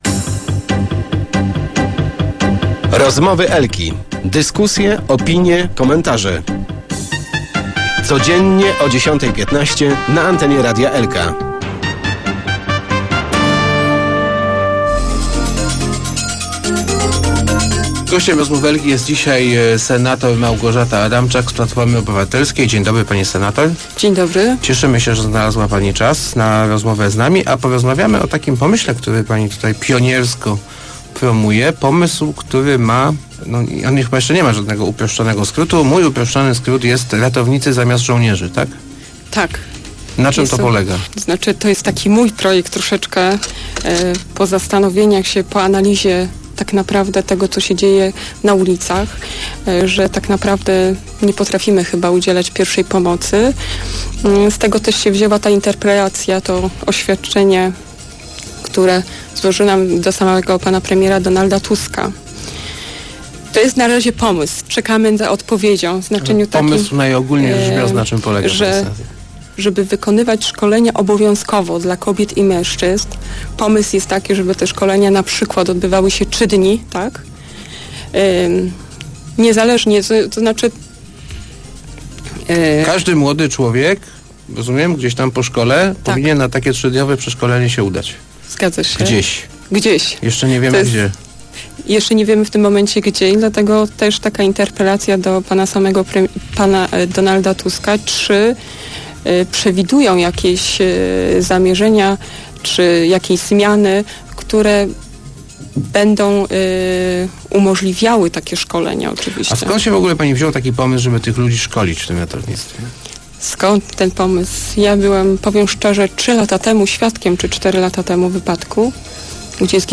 W ostatnim czasie ratowali ofiary wypadku na obwodnicy Śmigla - mówiła w Rozmowach Elki Adamczak - okazało się, że bardzo niewiele osób wie, jak udzielać pomocy.